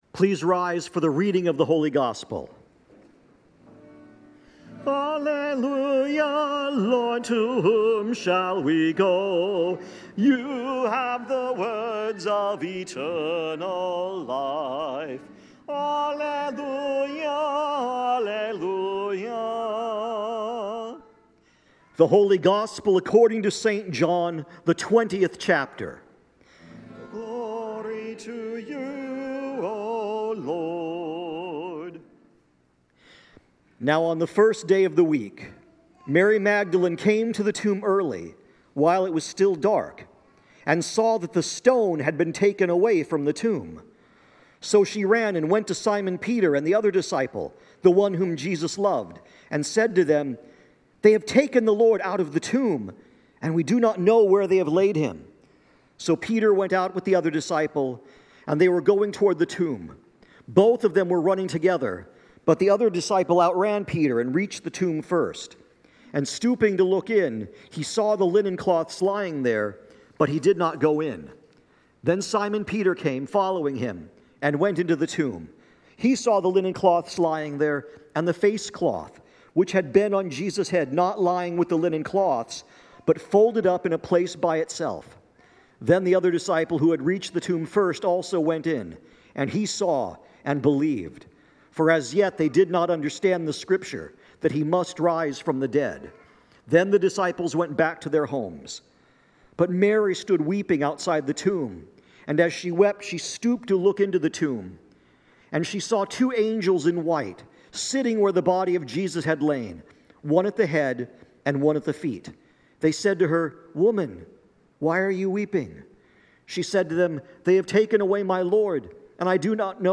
Apr 5, 2026 Easter Gospel Reading – Concordia Lutheran Church Findlay
Concordia Lutheran Church, Findlay, OH Apr 5, 2026 Easter Gospel Reading Play Episode Pause Episode Mute/Unmute Episode Rewind 10 Seconds 1x Fast Forward 30 seconds 00:00 / 00:03:04 Share Share Link Embed